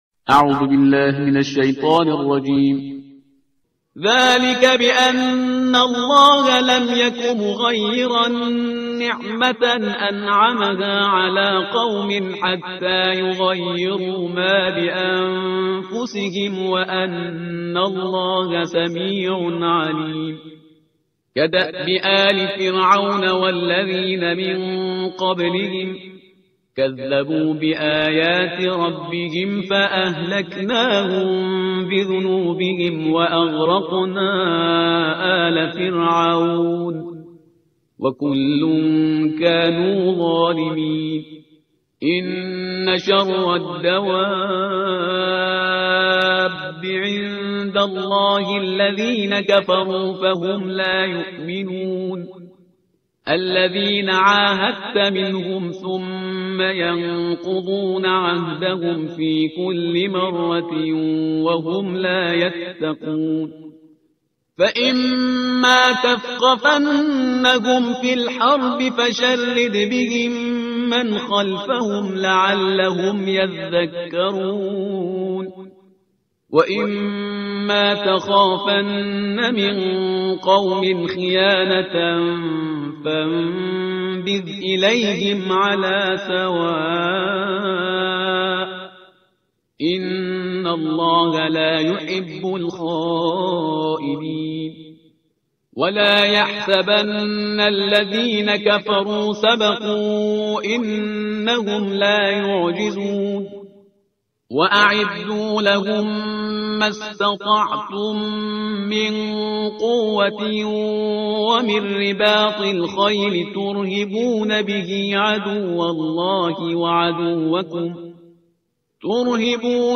ترتیل صفحه 184 قرآن با صدای شهریار پرهیزگار